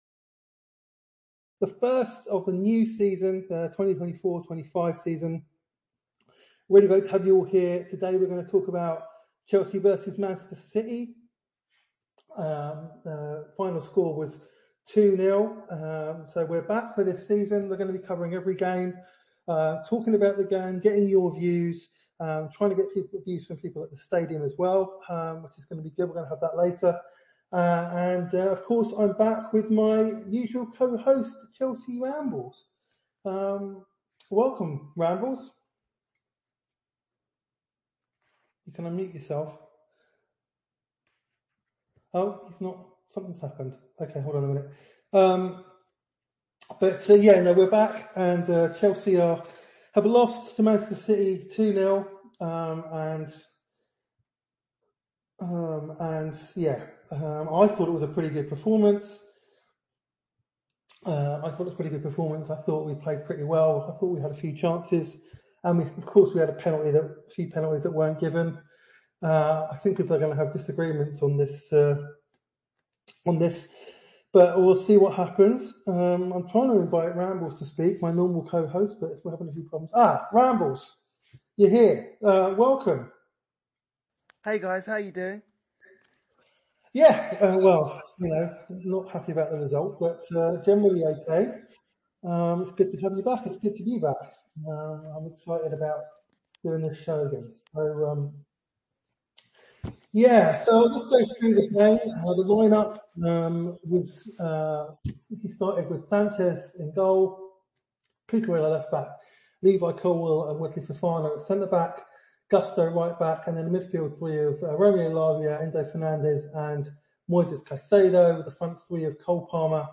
take your calls, discuss their thoughts and take you through Chelsea's first game of the season...